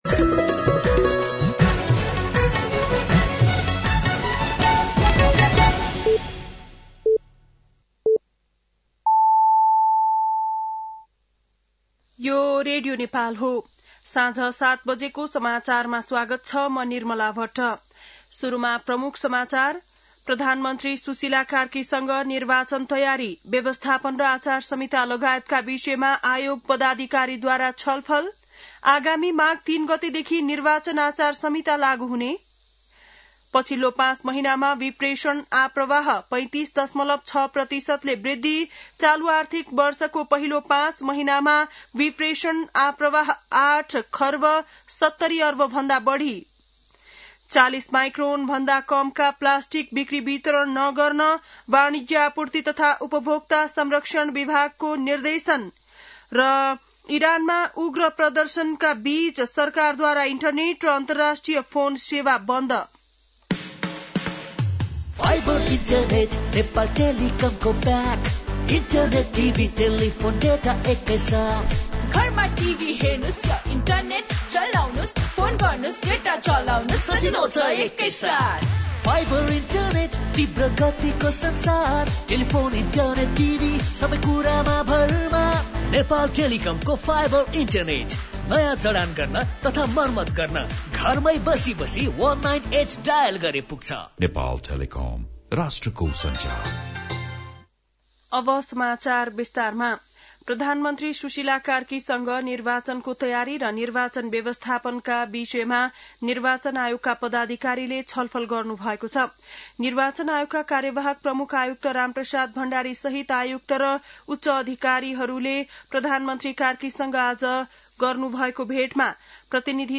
बेलुकी ७ बजेको नेपाली समाचार : २५ पुष , २०८२